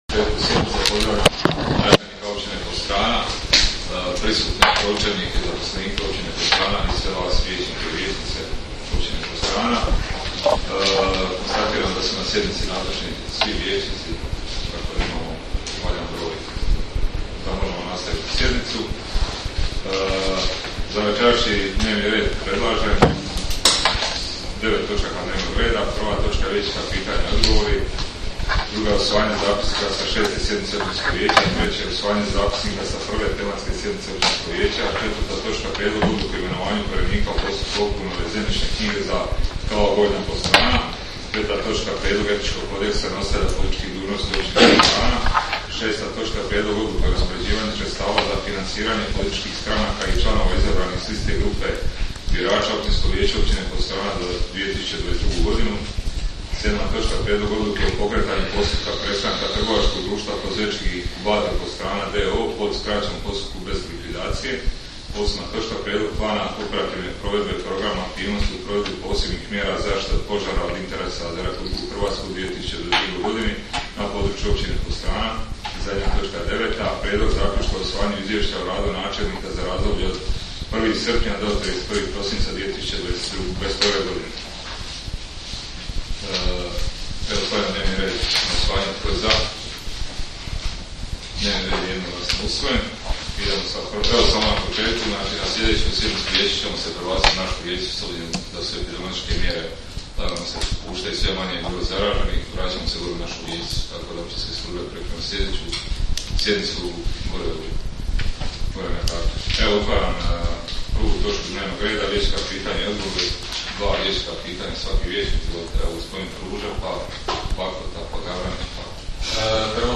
Sjednica će se održati dana 17. ožujka (četvrtak) 2022. godine u 19,00 sati u Sali za sastanke Općine Podstrana.